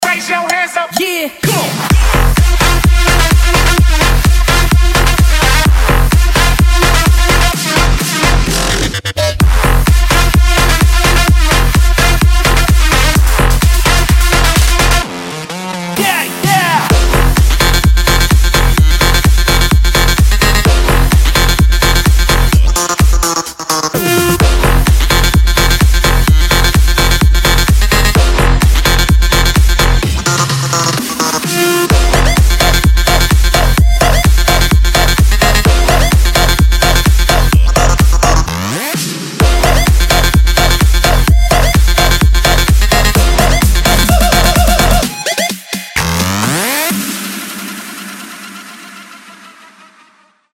electro house
dutch house